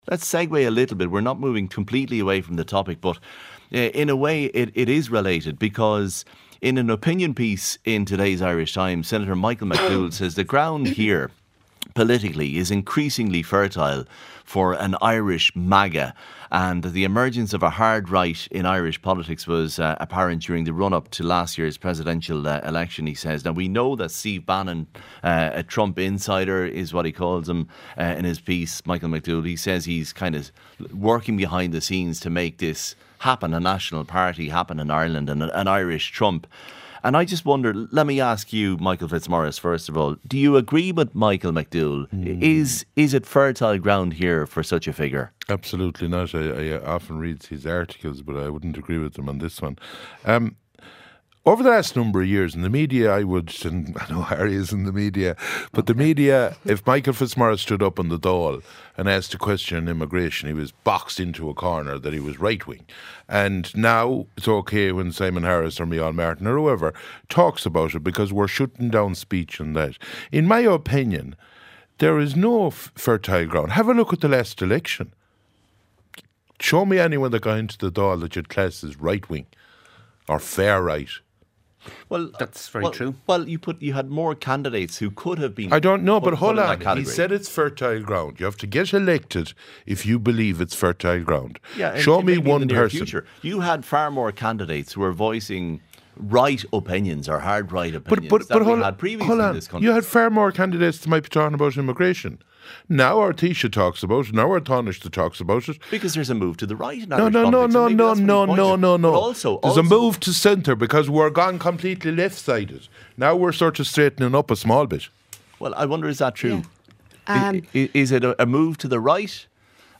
The Late Debate panel of Eileen Lynch, Fine Gael Senator, Malcolm Noonan, Green Party Senator, Michael Fitzmaurice, Independent TD for Roscommon-Galway